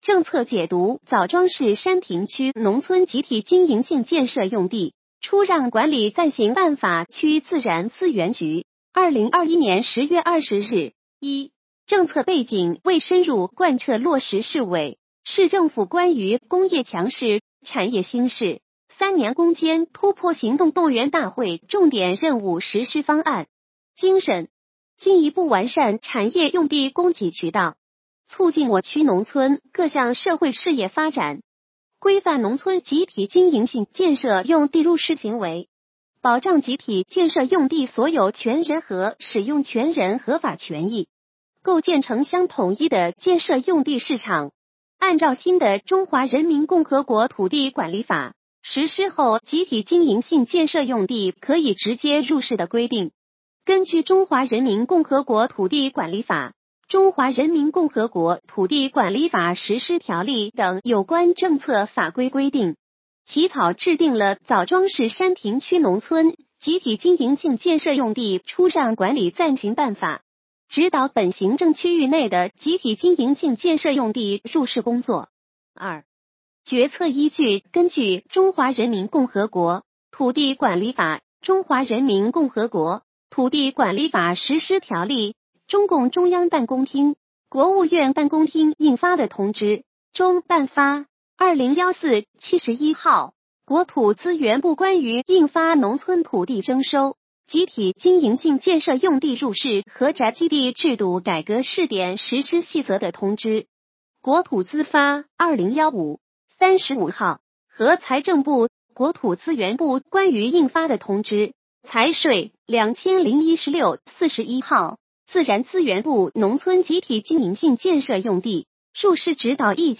语音解读：山亭区人民政府关于印发《枣庄市山亭区农村集体经营性建设用地出让管理暂行办法》的通知